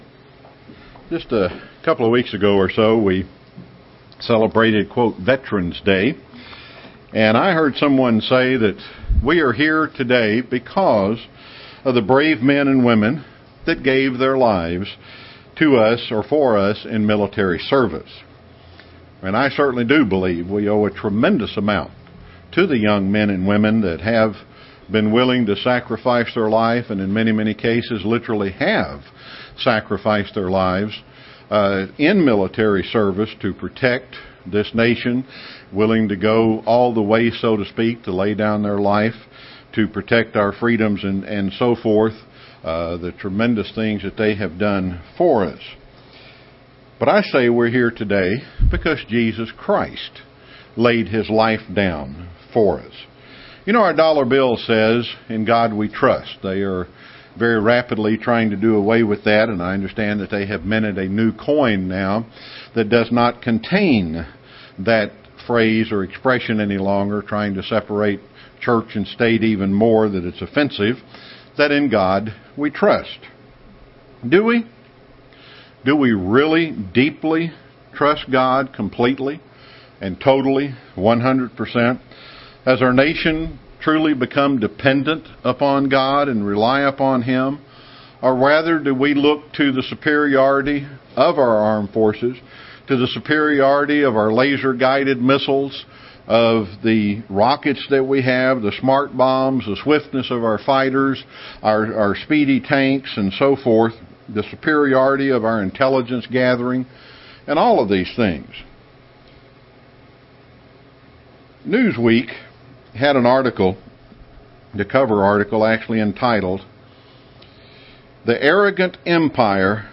This sermon is a continuation of an earlier sermon on Psalm 23, "The Lord Is My Shepherd".
Given in Chattanooga, TN